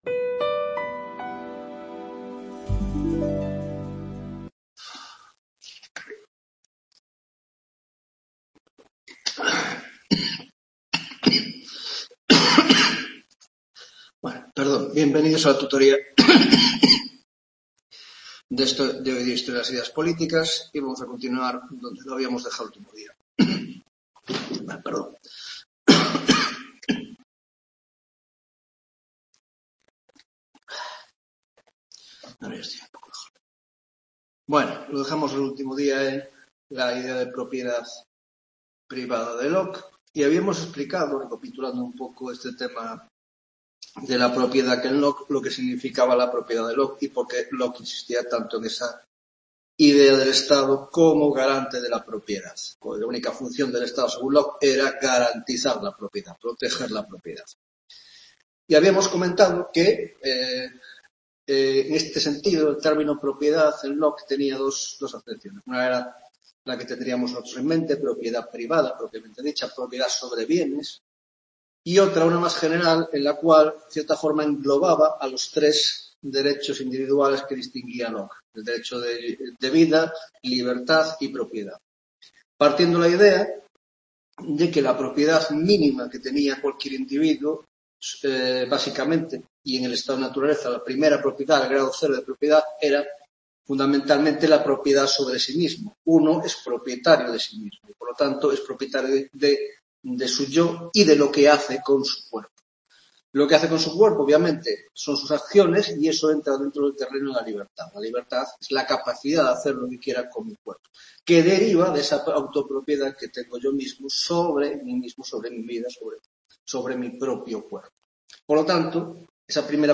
4ª Tutoria de Historia de las Ideas Políticas 2